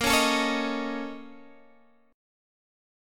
BbmM11 chord